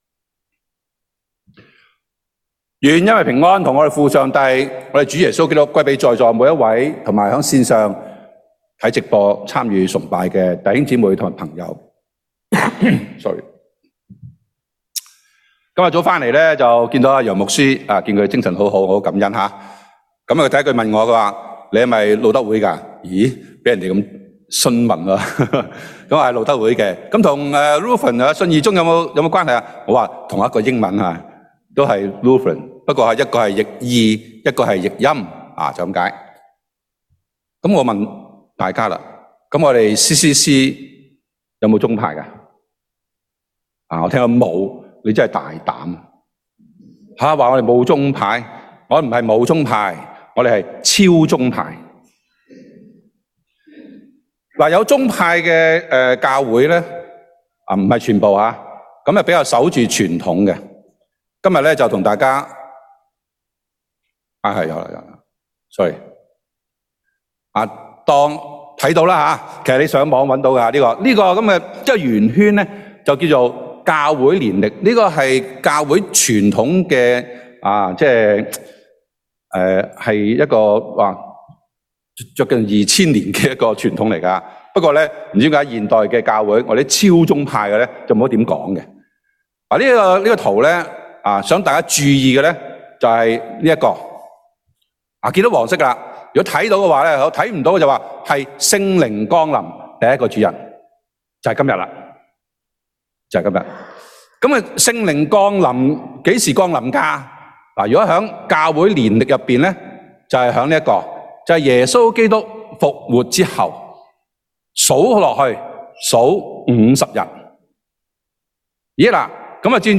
Service Type: Cantonese